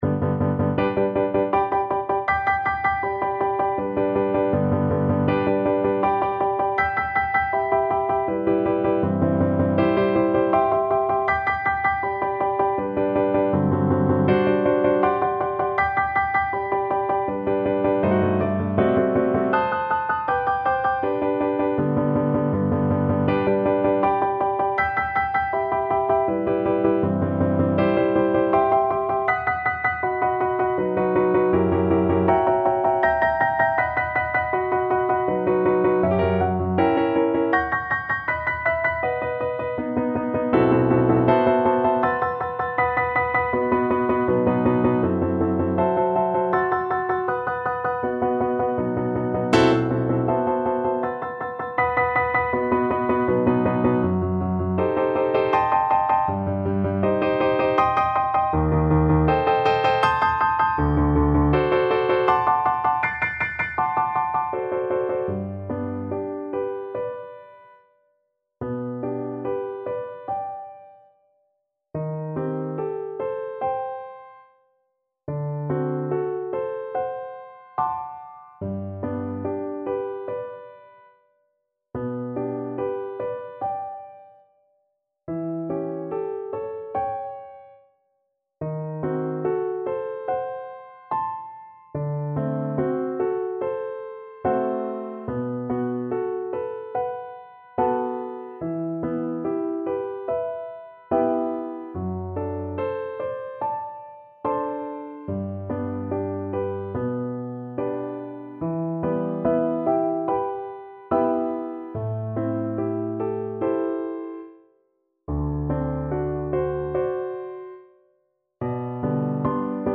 Andantino =80 (View more music marked Andantino)
3/4 (View more 3/4 Music)
Classical (View more Classical Viola Music)